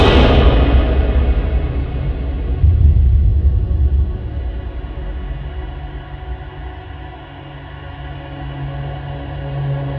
Нагнетание монстра:
monsters4.wav